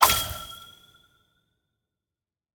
spawn_item1.ogg